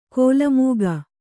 ♪ kōla mūga